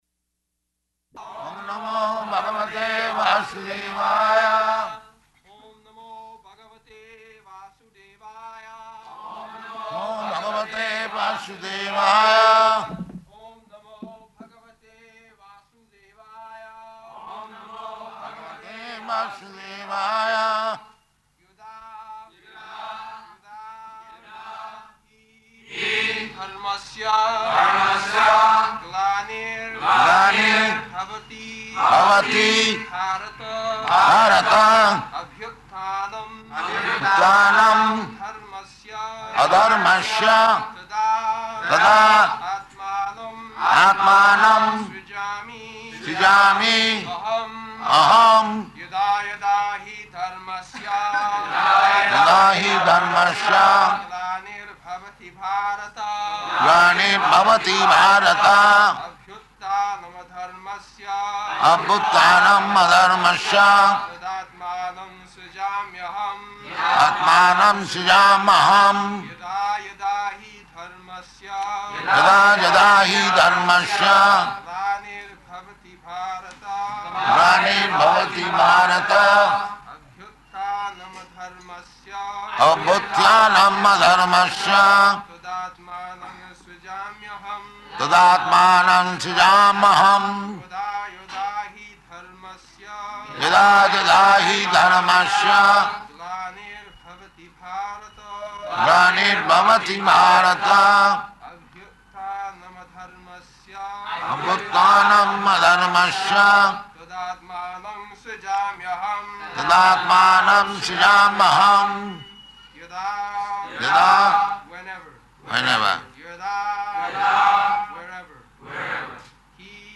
March 27th 1974 Location: Bombay Audio file
[Prabhupāda and devotees repeat] [leads chanting of verse]